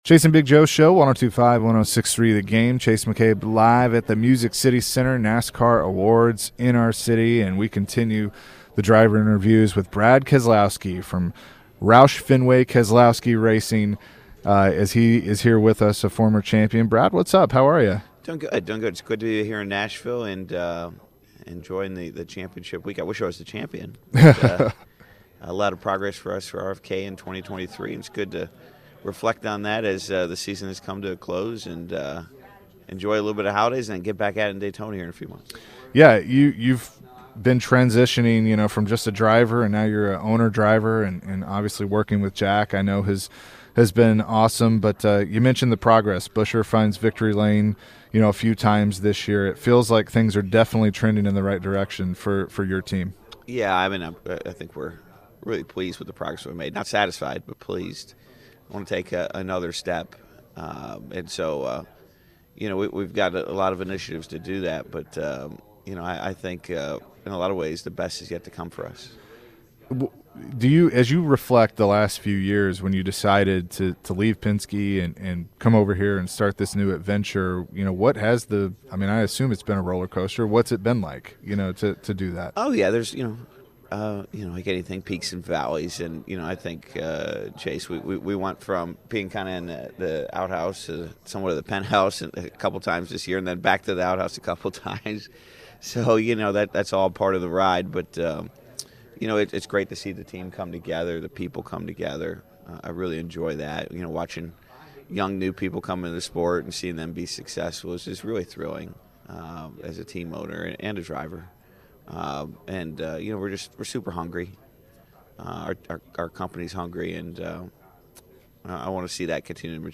NASCAR Driver Brad Keselowski at today's NASCAR Awards celebration.